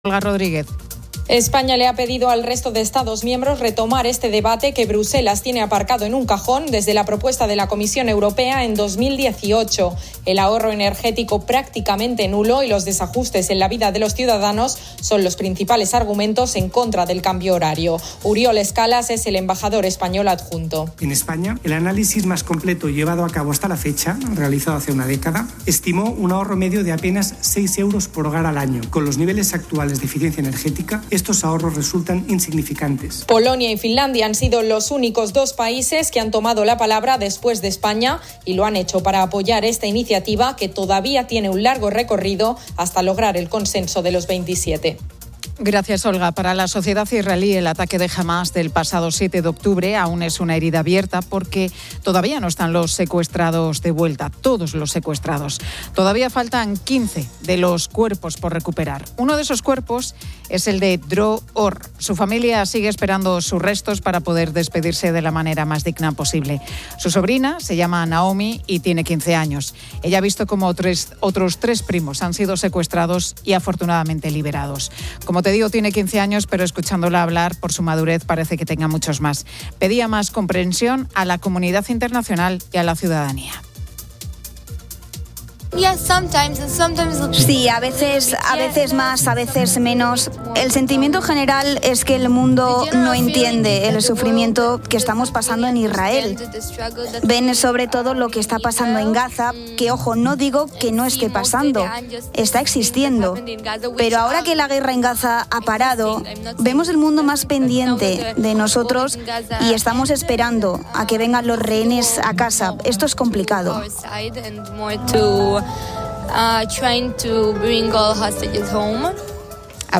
España pide retomar el debate del cambio horario en la UE, donde las dudas sobre los ahorros energéticos son clave. El conflicto Israel-Hamas prosigue con la incertidumbre del alto el fuego; Ángel Expósito de COPE reporta desde Jerusalén sobre la entrega de rehenes, el dolor de las familias por los secuestrados y la desconfianza mutua. El Tribunal Supremo valida grabaciones de narcos en un caso con 120 afectados en España.